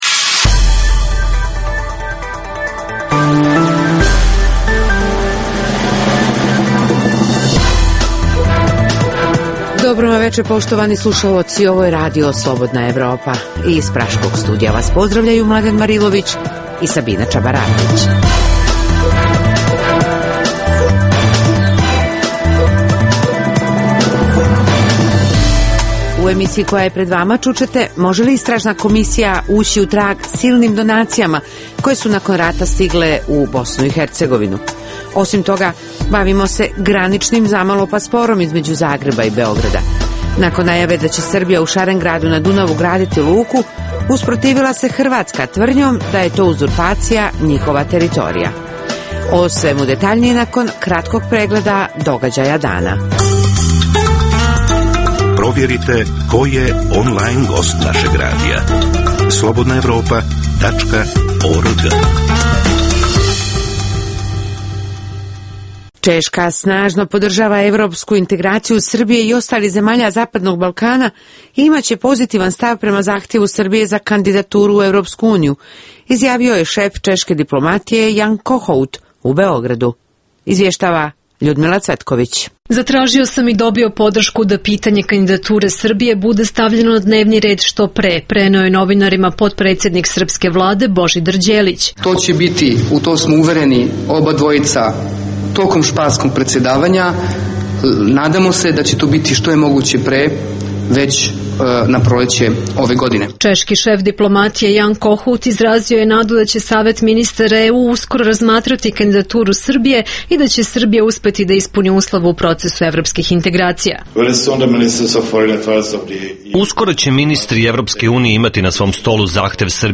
Nakon najave da će Srbija u Šarengradu na Dunavu graditi luku, usprotivila se Hrvatska tvrdnjom da je to uzurpacija njihova teritorija. Što kažu zvaničnici, a što ljudi koji žive u pograničnom području, čućete od naših reportera koji su posjetili obale Dunava.